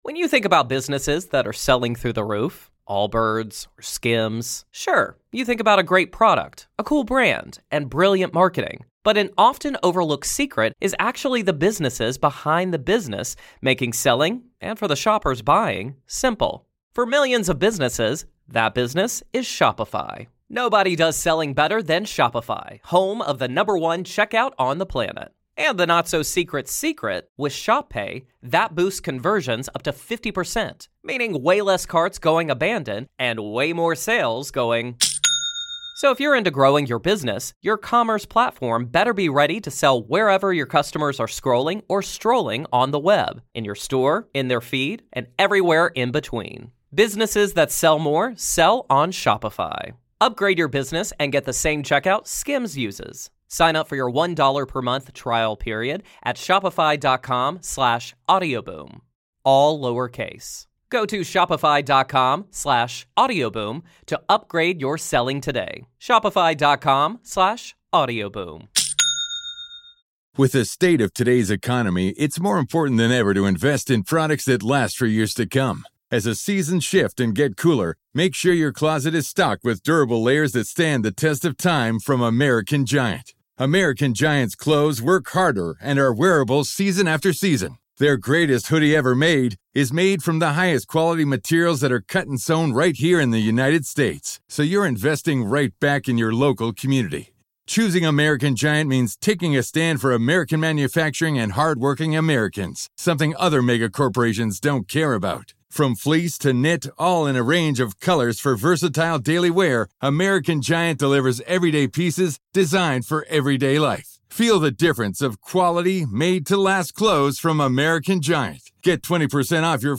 Your Nightly Prayer 🙏🏾 Psalm 16:11